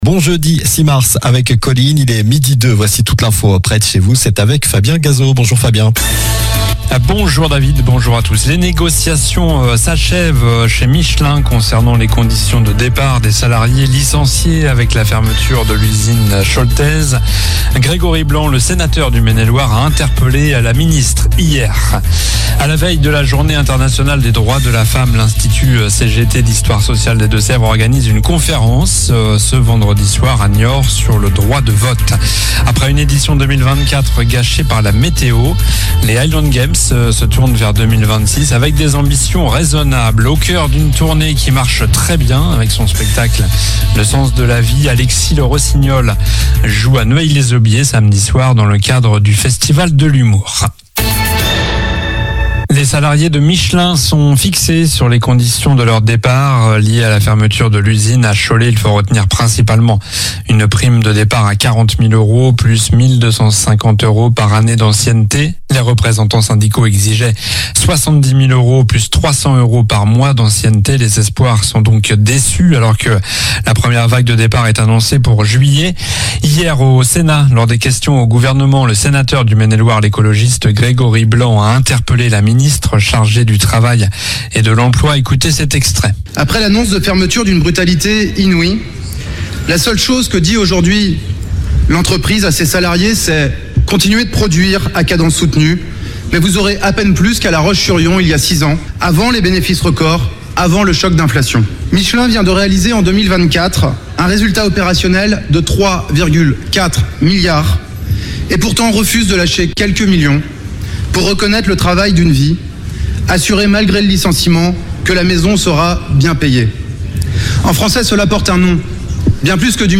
Journal du jeudi 06 mars (midi)